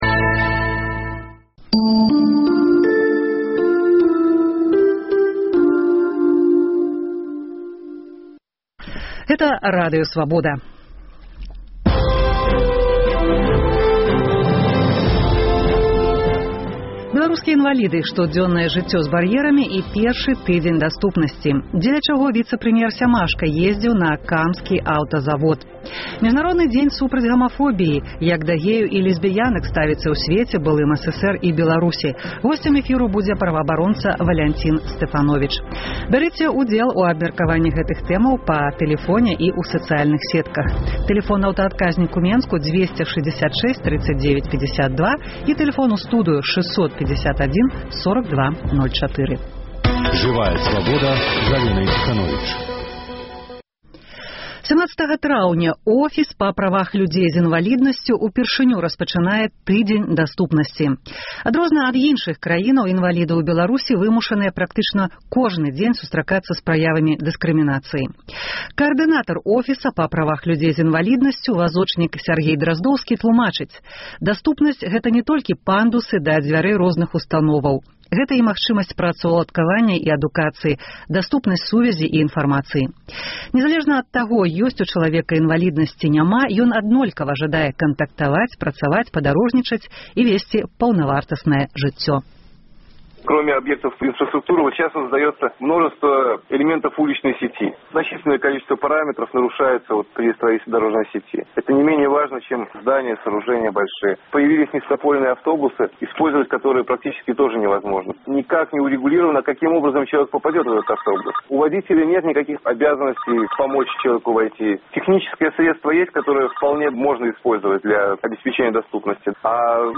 Міжнародны дзень супраць гамафобіі: як да геяў і лесьбіянак ставяцца ў сьвеце, былым CССР і Беларусі. Госьць эфіру –праваабаронца